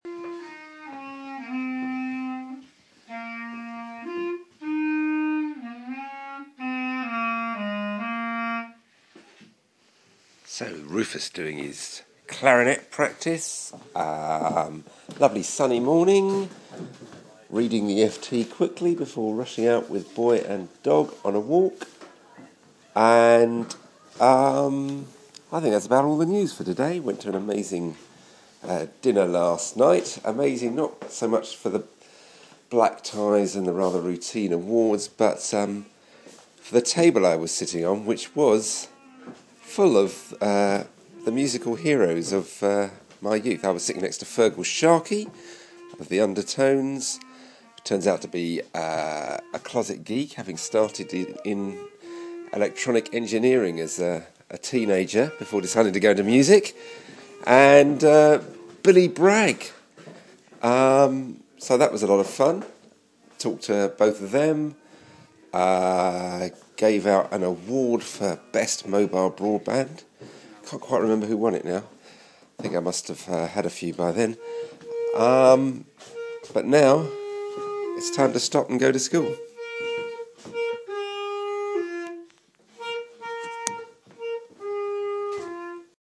Morning Boo With Clarinet